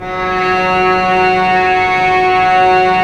Index of /90_sSampleCDs/Roland L-CD702/VOL-1/STR_Vcs Bow FX/STR_Vcs Sul Pont